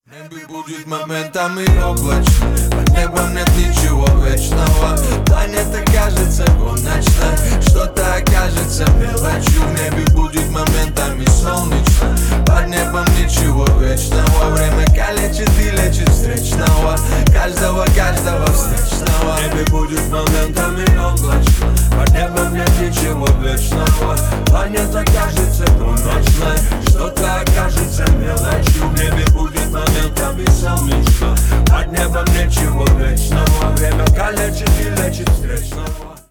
душевные
ремиксы , рэп